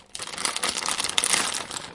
描述：部分来自原始录音，用zoom h2n拍摄。
Tag: 噼啪 裂纹 开裂 饼干 随机的 碾碎 紧缩 嘎吱嘎吱